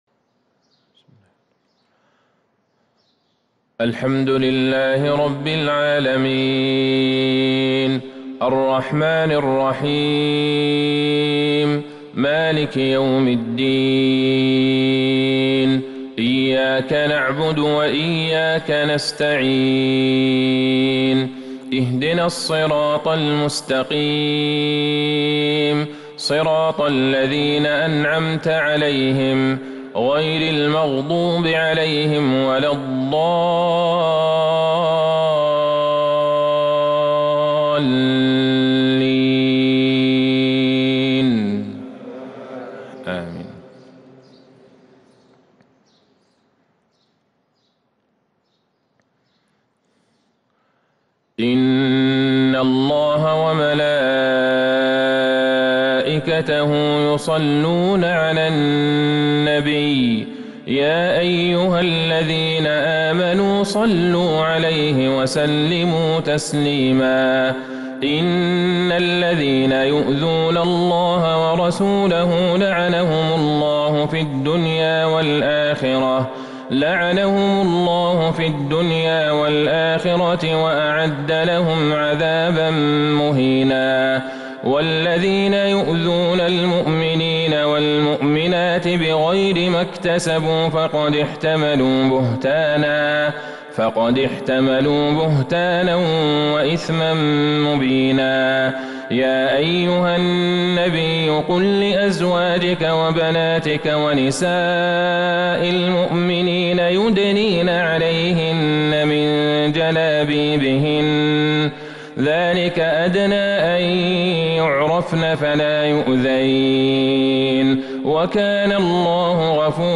فجر الجمعة | ٢٧ شعبان ١٤٤٢هـ | خواتيم الأحزاب وفواتح سبأ | Fajr prayer from Surah Al-Ahzab & Saba 9-4-2021 > 1442 🕌 > الفروض - تلاوات الحرمين